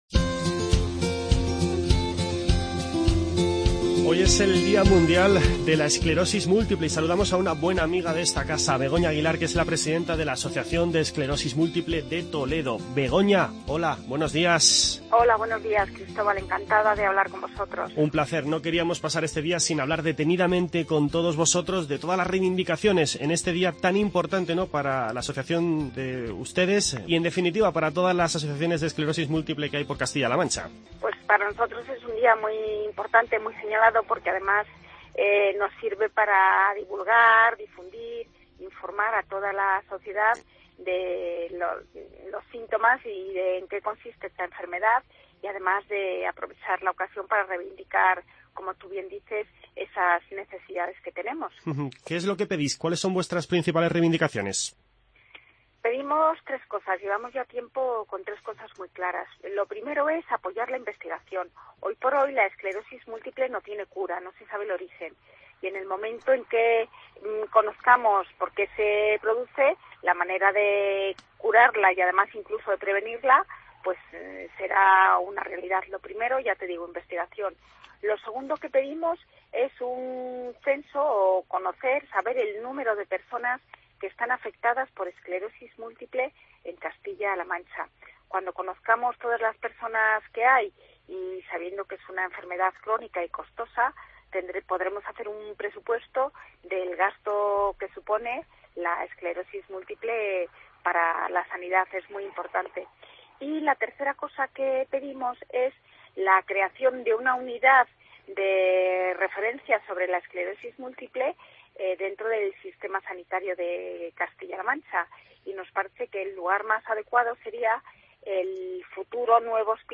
En nuestra primera entrevista hablamos del Día Mundial de la Esclerosis Múltiple.